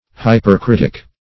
Search Result for " hypercritic" : The Collaborative International Dictionary of English v.0.48: Hypercritic \Hy`per*crit"ic\, n. [Pref. hyper- + critic: cf. F. hypercritique.]